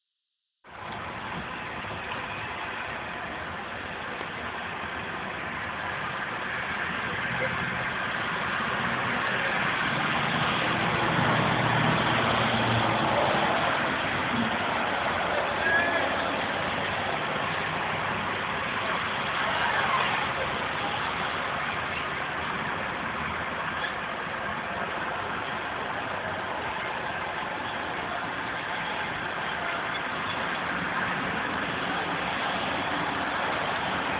Field Recording #3
Location: Hempstead Turnpike Sound: Cars driving by, voices Voice0004